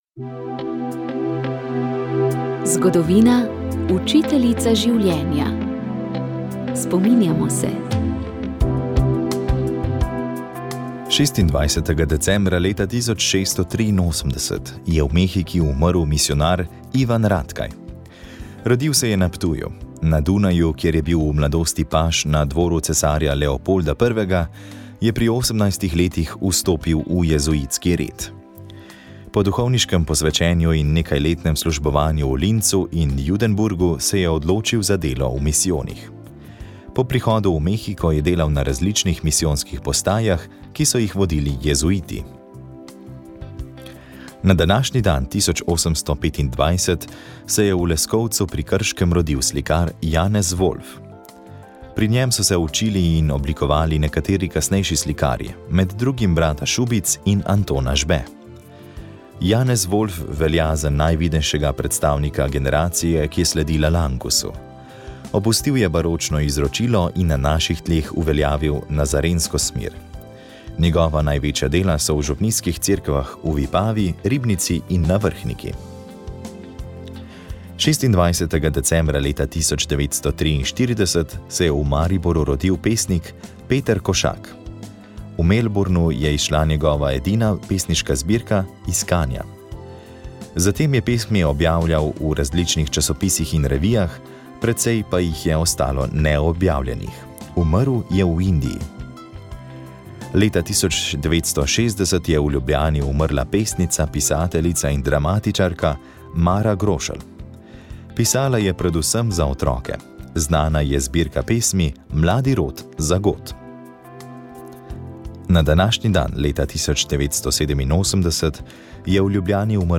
Zato smo na božični dan v oddaji Pogovor o prisluhnili trem materam, ki imajo skupaj 10 otrok.